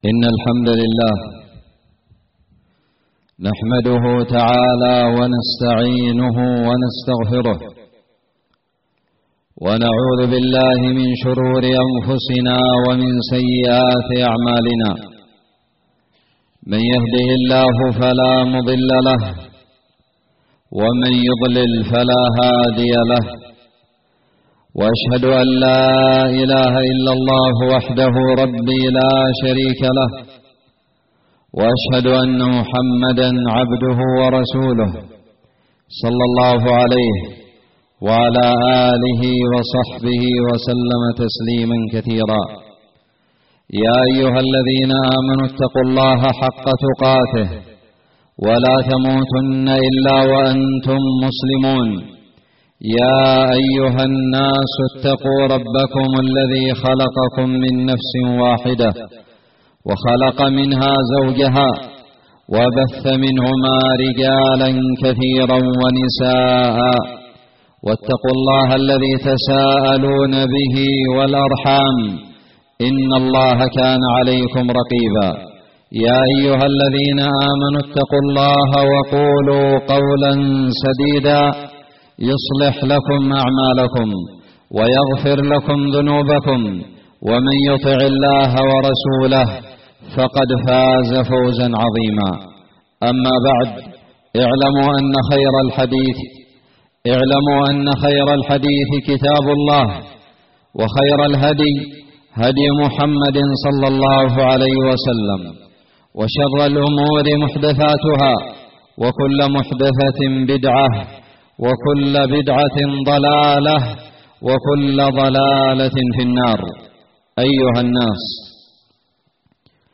خطب الجمعة
ألقيت بدار الحديث السلفية للعلوم الشرعية بالضالع في 1 شوال 1440هــ